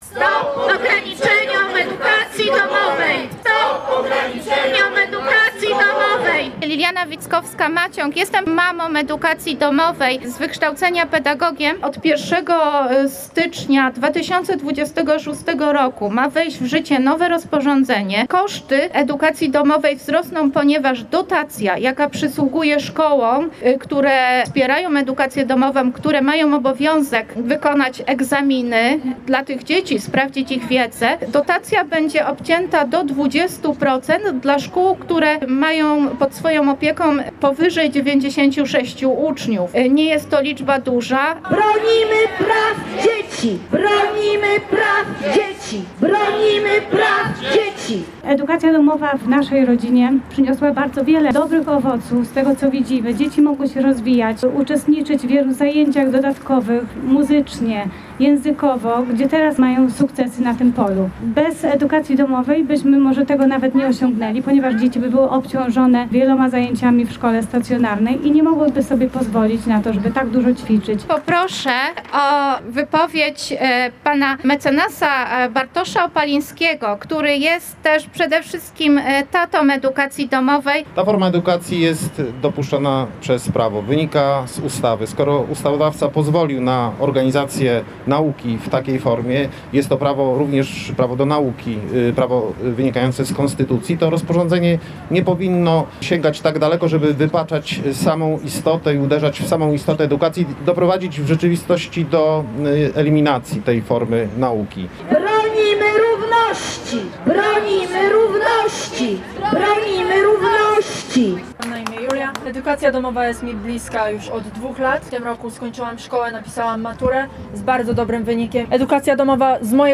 Uczestnicy protestu mówili także o korzyściach wynikających z edukacji domowej, np. tempa nauczania dostosowanego do konkretnego dziecka i większej ilości czasu dla dzieci na rozwijanie swoich pasji.